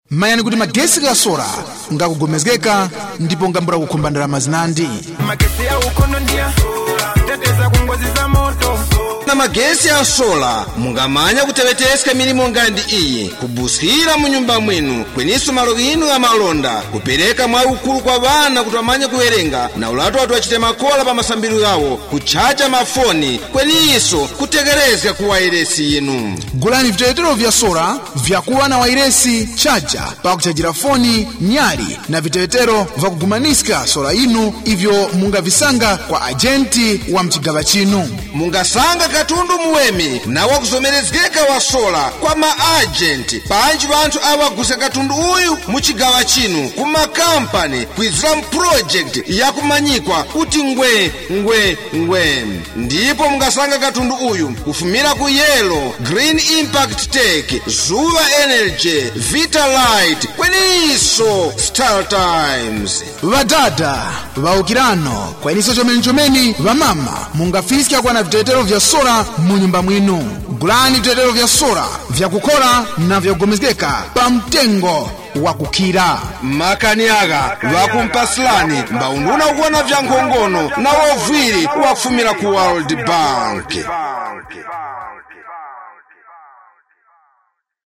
TUMBUKA  benefits of solar spot.mp3